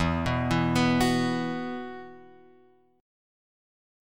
E Suspended 2nd